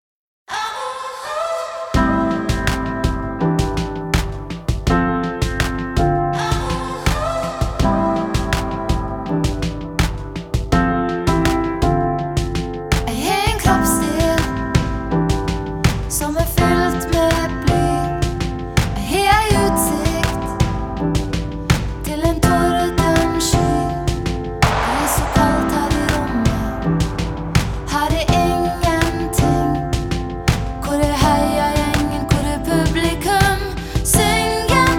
# Adult Contemporary